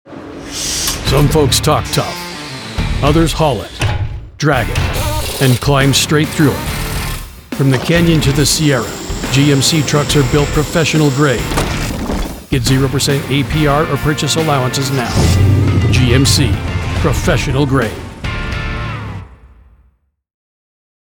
Gritty, Rugged, American Voiceover
• Gritty, masculine tone with Western authenticity
• Cinematic pacing perfect for national TV and radio
Truck Commercial Voiceover Demos
• Fully treated booth